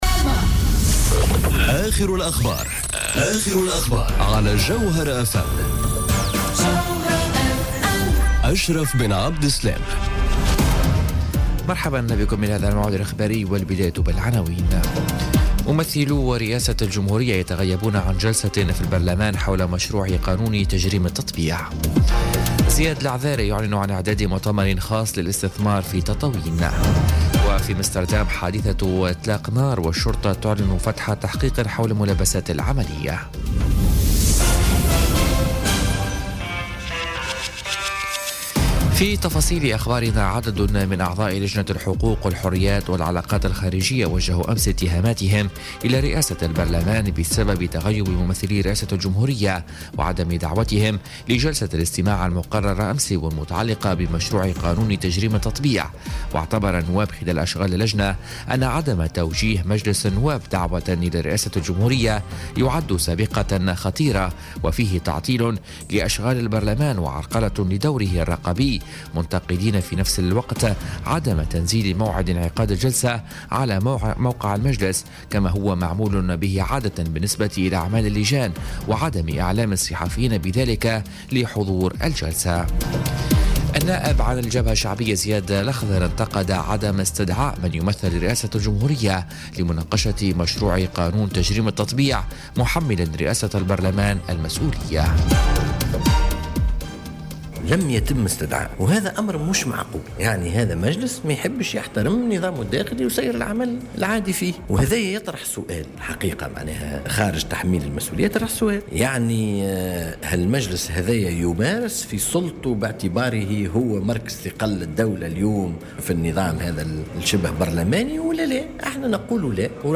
Journal Info 00h00 du samedi 27 Janvier 2018